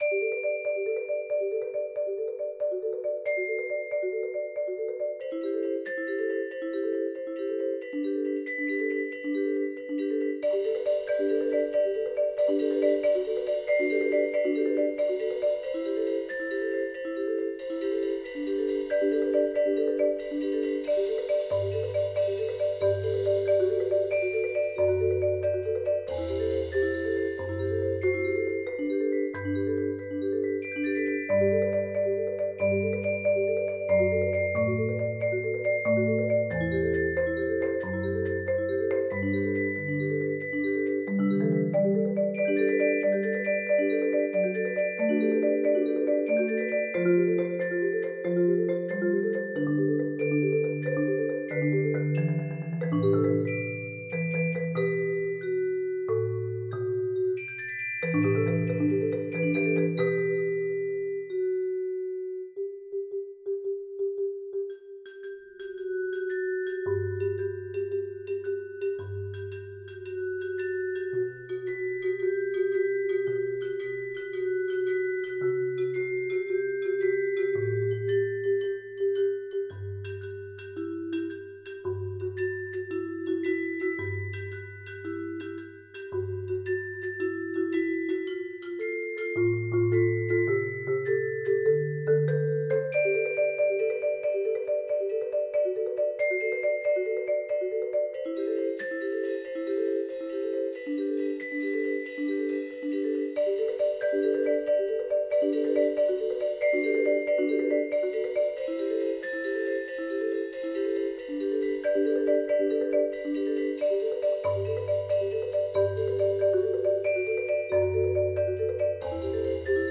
Voicing: Mallet Quartet